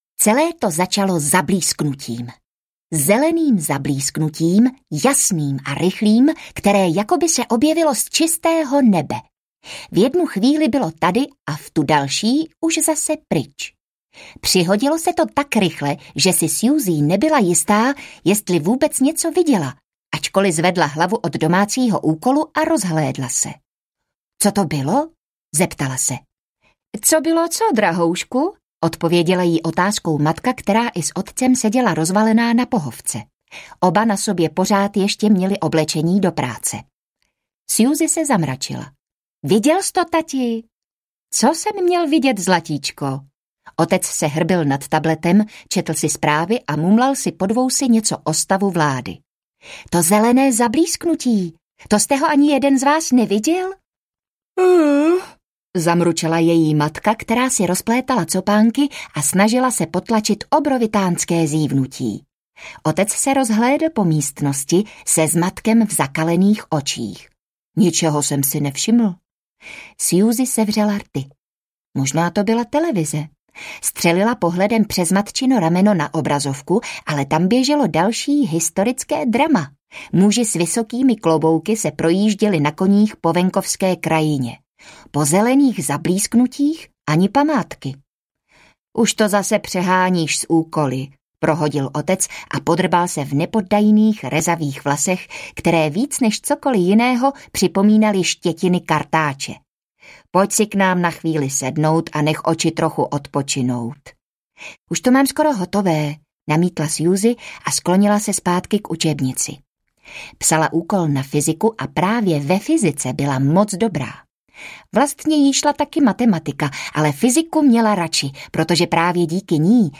Vlak do Zázračných světů – Prokletá zásilka audiokniha
Ukázka z knihy